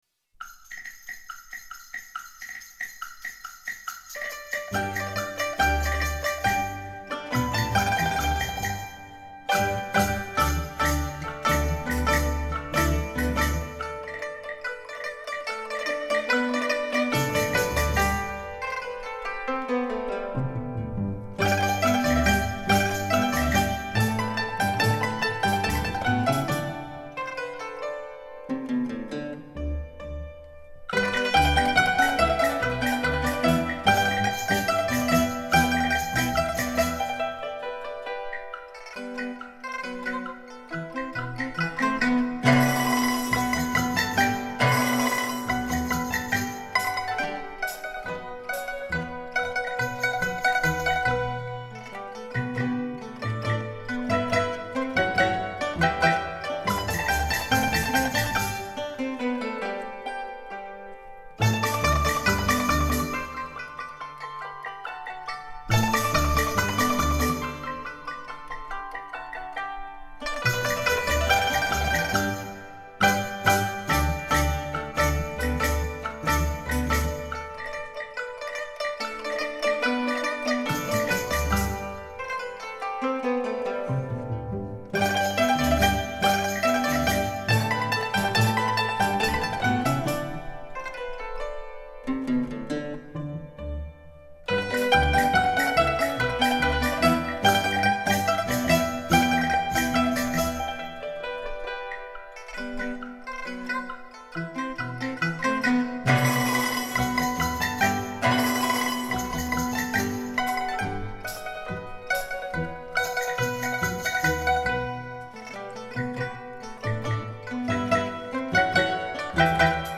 弹拨乐合奏